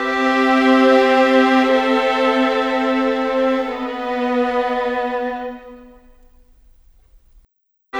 Cinematic 27 Strings 03.wav